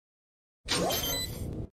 Valorant Fake Defuse Effect Sound Effect Free Download